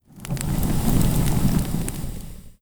Burning